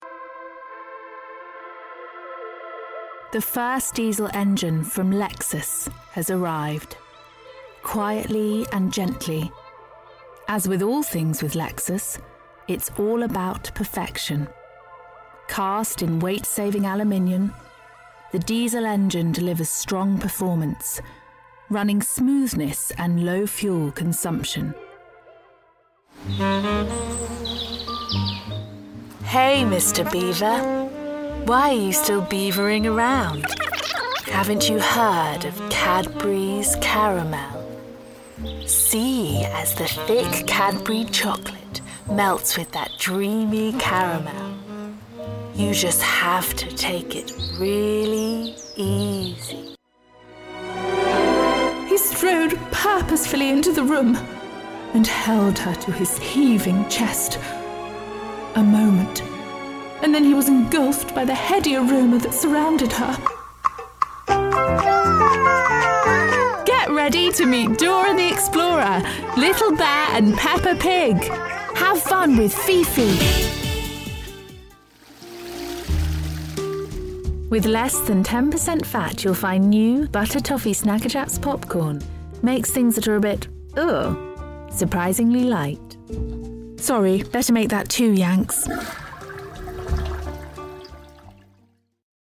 Warm, Friendly and Confident